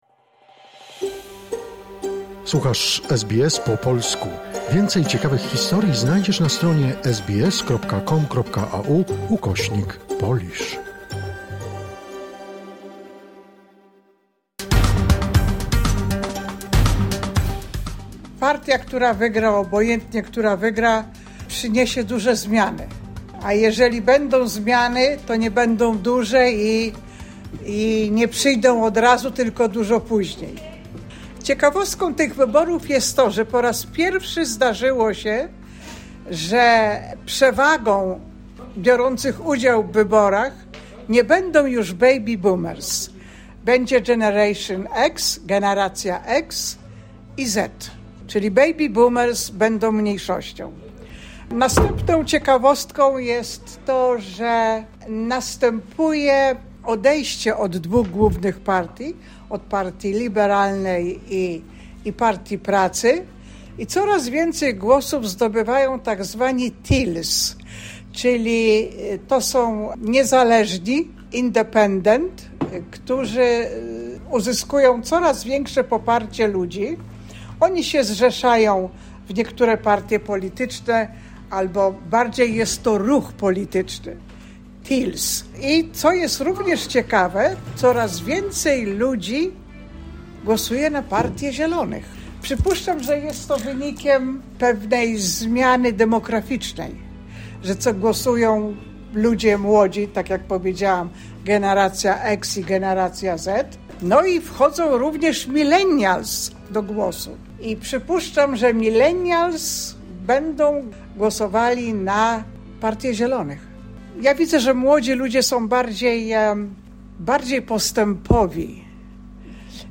Wybory federalne 2025 - wypowiedzi Polaków w Australii..
Czy nadchodzące australijskie wybory federalne wpłyną na zmianę wizerunku Australii? Posłuchajmy, co sądzą nasi słuchacze – Polacy mieszkający w Australii...